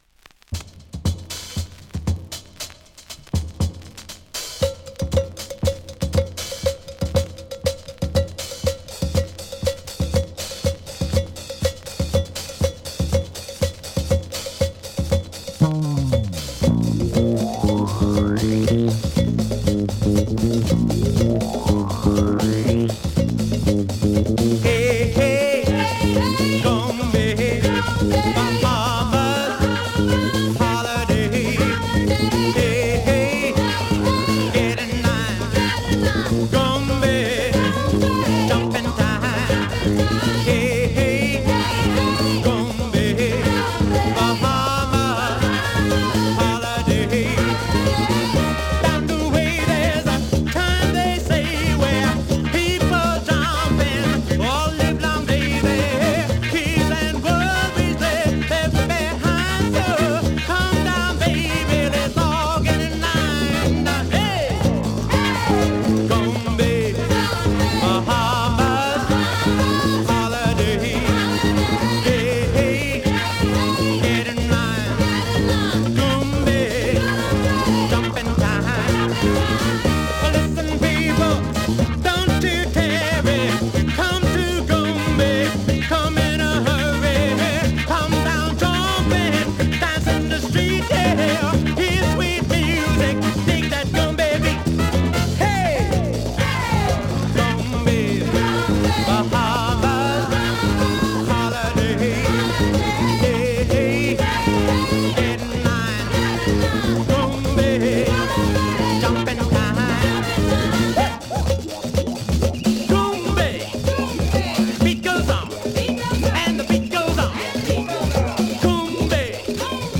(Vocal)
(Instrumental) アイランドファンク